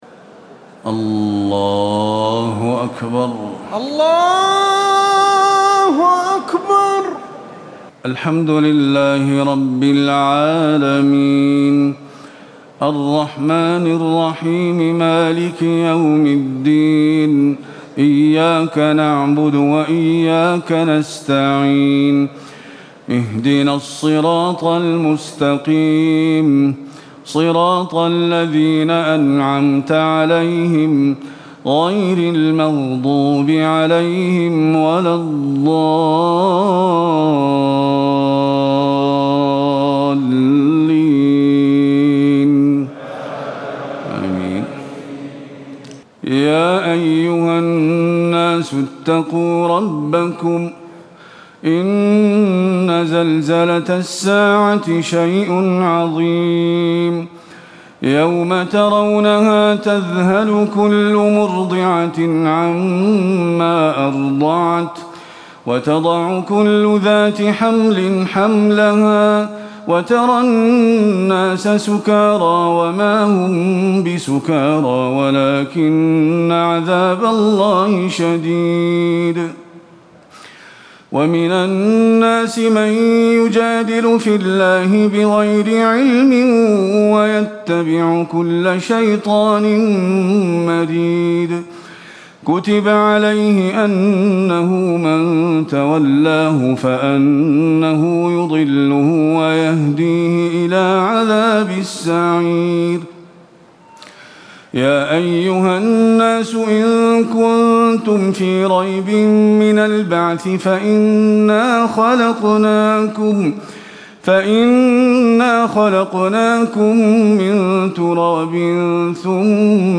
تراويح الليلة السادسة عشر رمضان 1437هـ سورة الحج كاملة Taraweeh 16 st night Ramadan 1437H from Surah Al-Hajj > تراويح الحرم النبوي عام 1437 🕌 > التراويح - تلاوات الحرمين